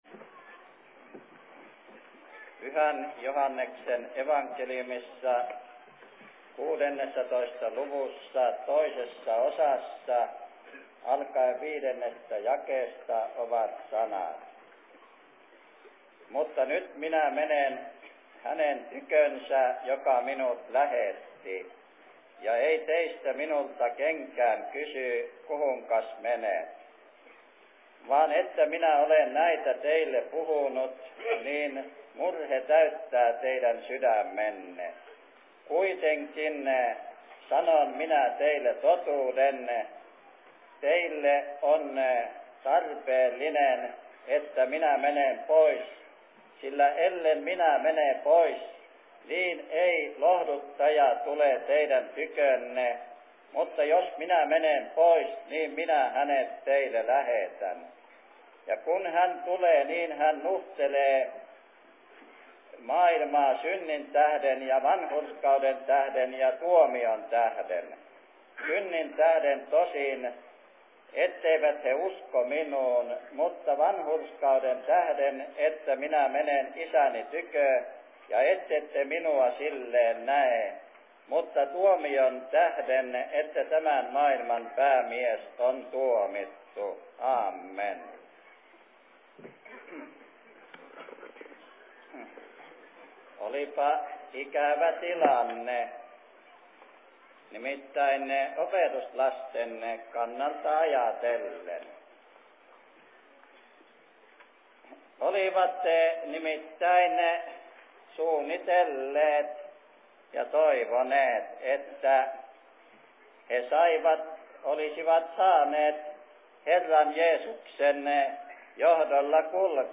2. radiosaarna 01.07.1967
Paikka: 1967 Suviseurat Jämsässä